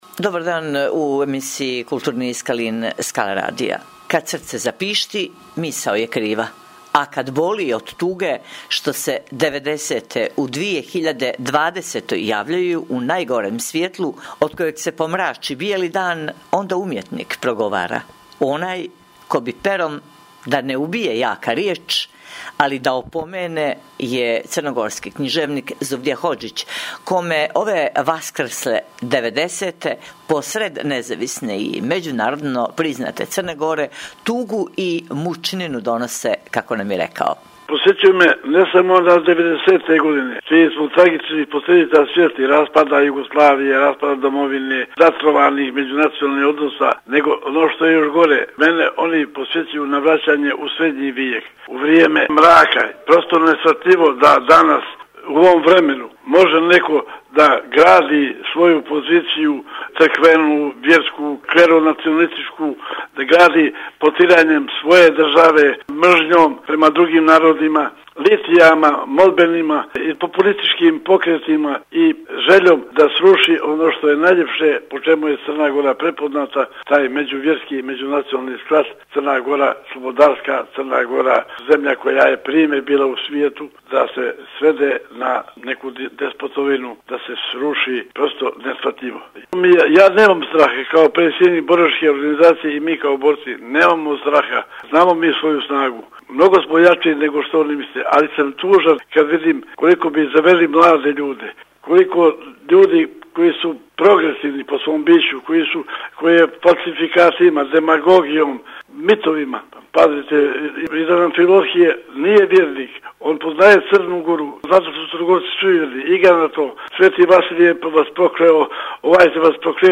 Skalinima kulture na talasima Skala radija subotom u 14 sati i 30 minuta.